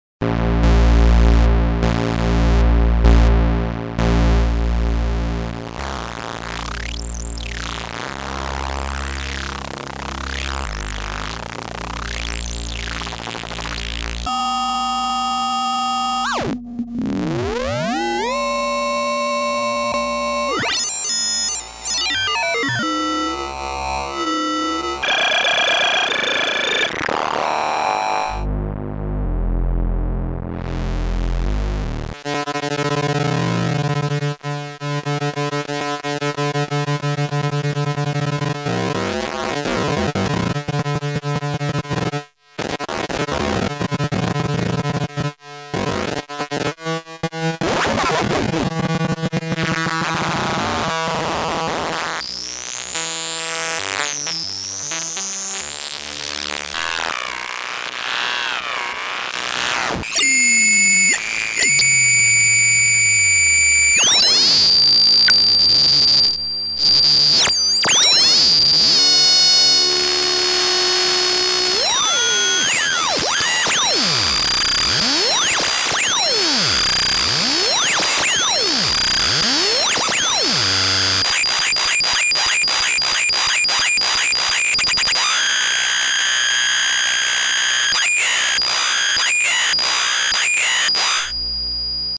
A quite complete variable carrier ring modulator effect with modulation and envelope follower.
demo with RSF KOBOL